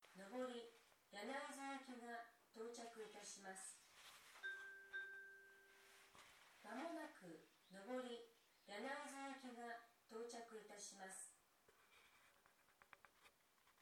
この駅では接近放送が設置されています。
接近放送普通　柳津行き接近放送です。